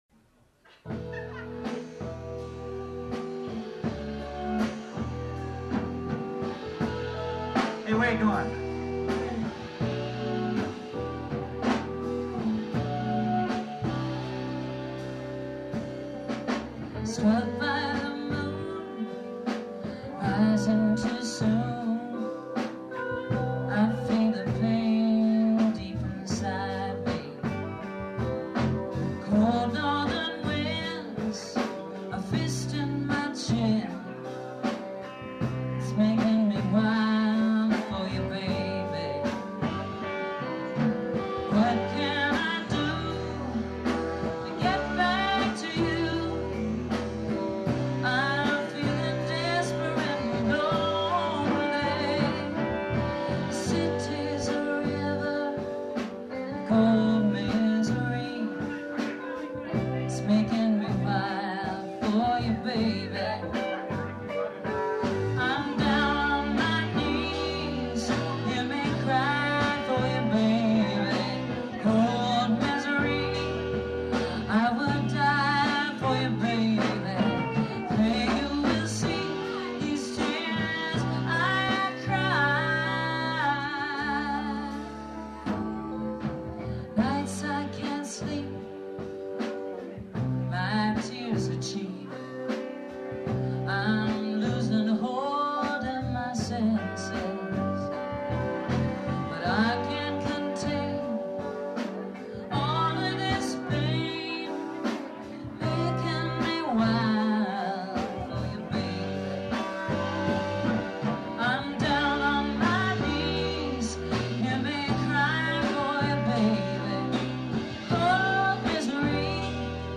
Electric Bassist in Los Angeles For Approximately Four Years
Private Party live at the Sheraton Round Barn Inn in Santa Rosa playing "Wild For You Baby"